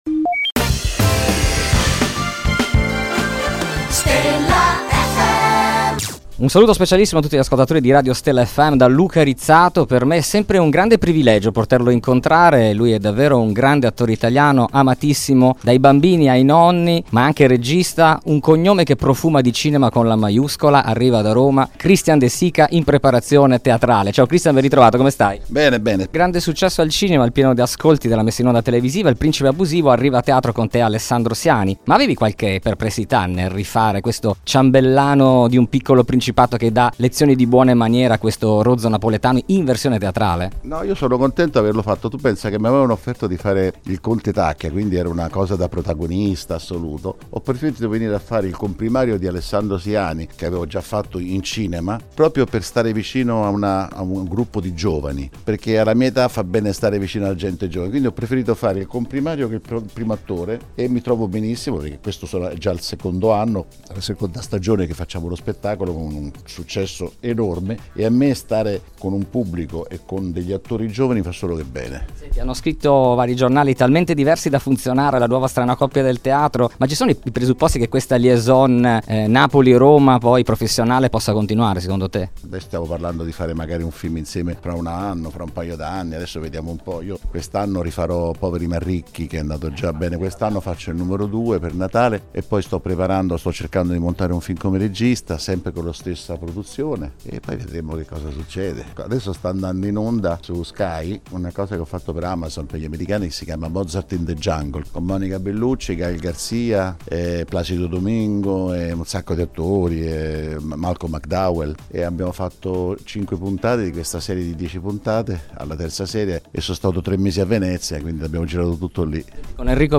06 Mar Intervista Christian De Sica
Intervista esclusiva per Stella Fm ad un grande mito del Cinema e Spettacolo Italiano: CHRISTIAN DE SICA!